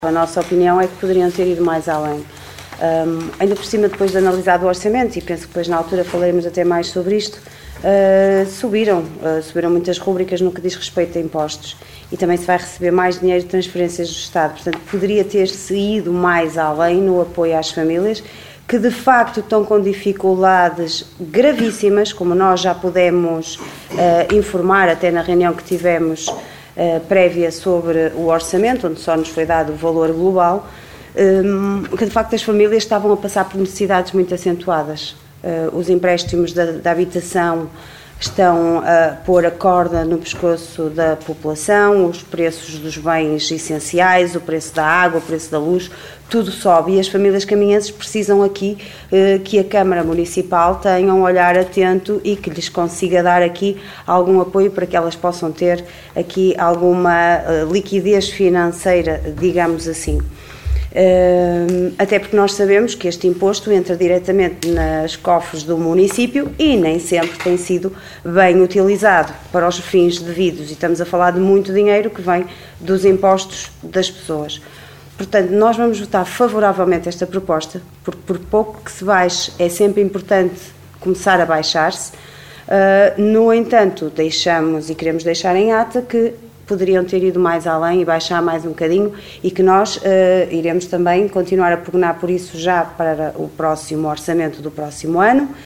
Reuniao Camarararia 30 Nov 2023